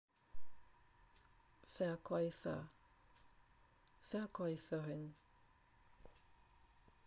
Klikom na strelicu čućete izgovor svake reči koja označava profesiju.